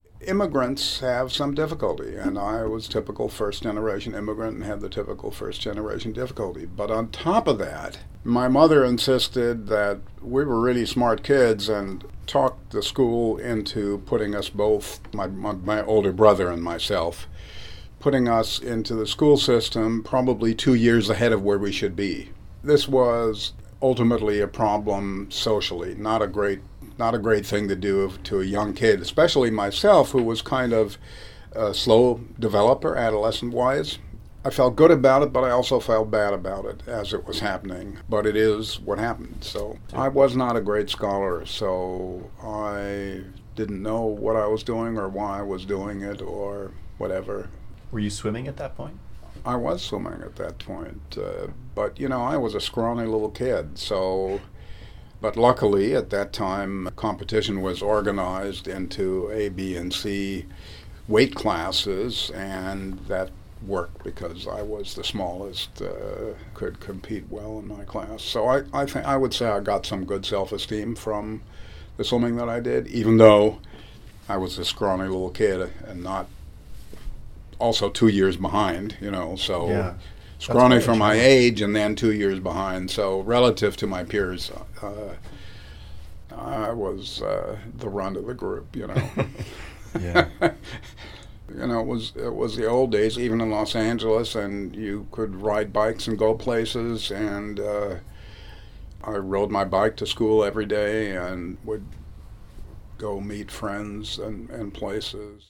In this next excerpt, Dr. Bentler recalls some of his childhood interests and activities growing up in LA: